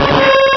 sovereignx/sound/direct_sound_samples/cries/ariados.aif at master